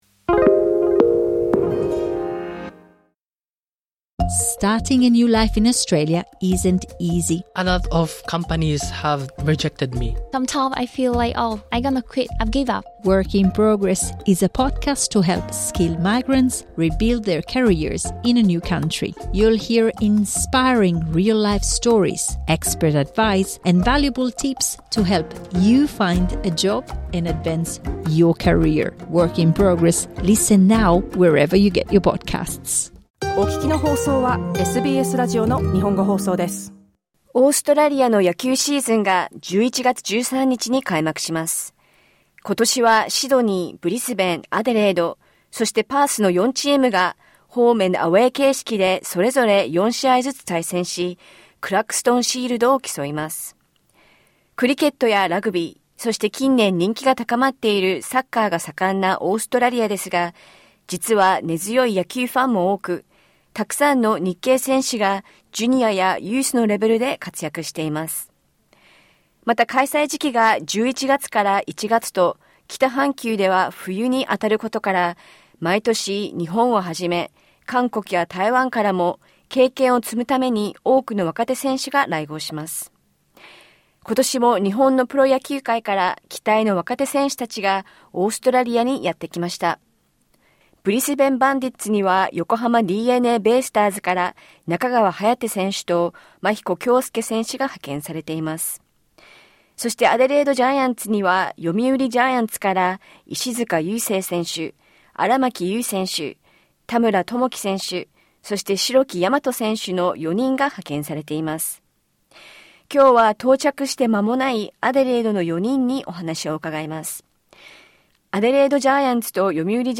Once again this year, young players from Japan’s professional baseball leagues have come to test their skills abroad. We spoke with four players from the Yomiuri Giants who have been sent to Adelaide Giants.